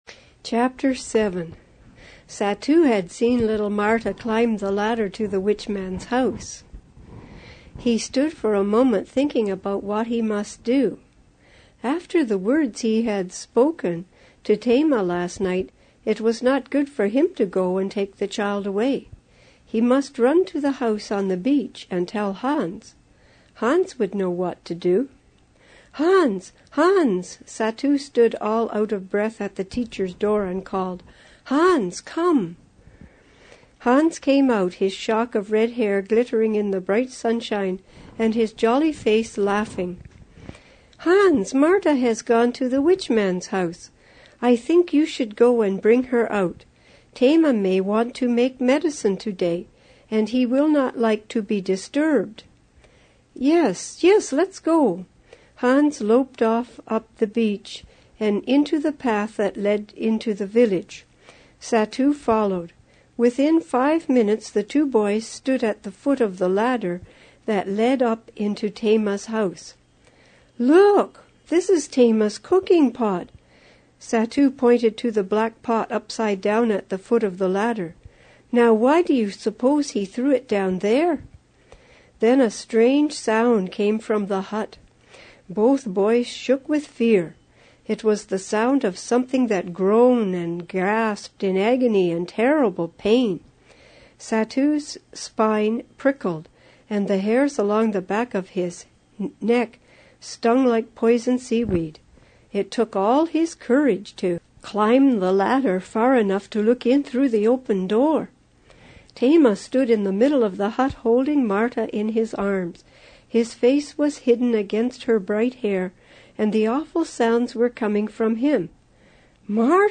Granny Reads
An Exciting True Mission Story Book in Audio MP3